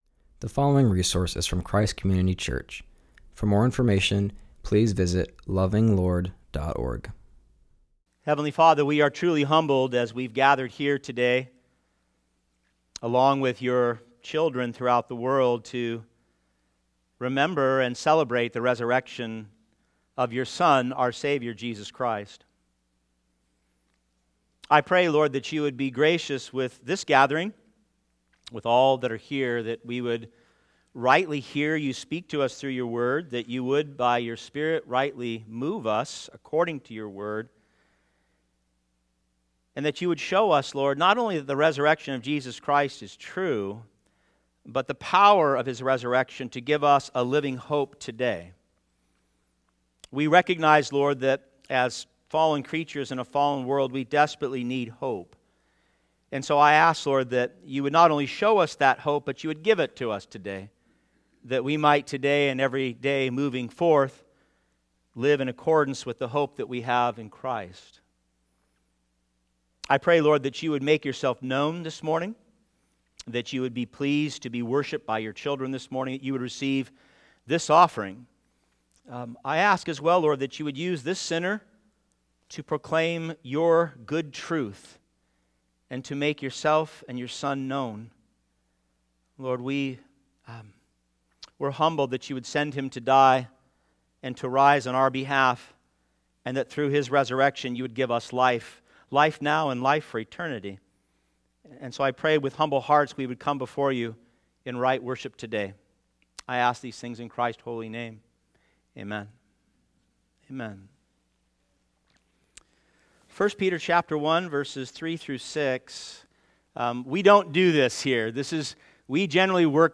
preaches from 1 Peter 1:3-6.